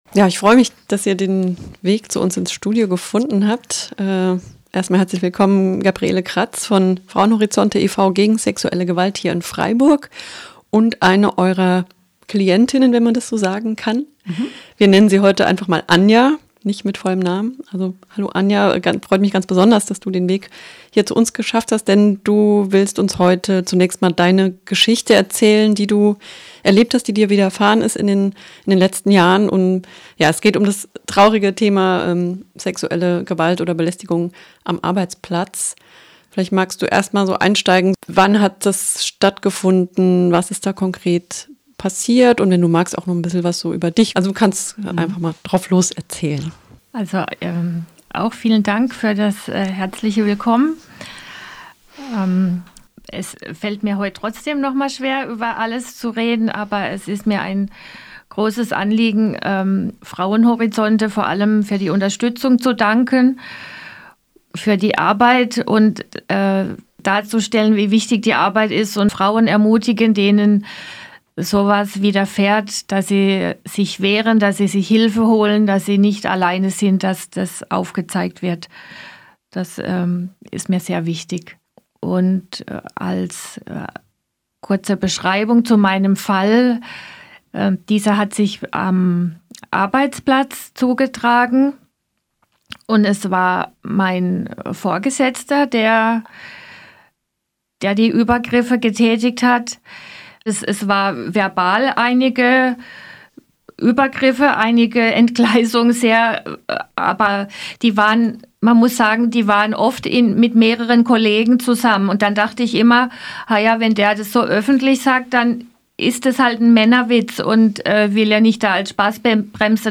Eine Audiodokumentation zu sexueller Belästigung am Arbeitsplatz in Kooperation mit Frauenhorizonte Freiburg e.V. (Ein Interview zur Arbeit von Frauenhorizonte findet ihr hier.)